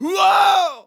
Short wow Scream Sample
Categories: Vocals Tags: dry, fill, male, sample, Scream, short, Tension, wow
TEN-vocal-fills-100BPM-A-10.wav